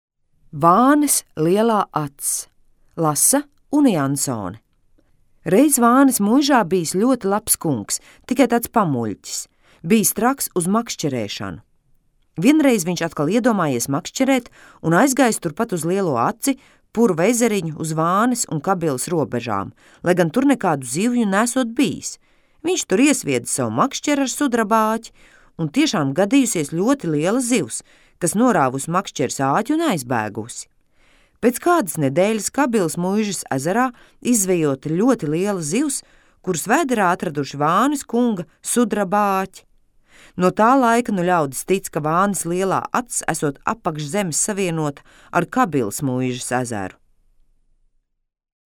Teikas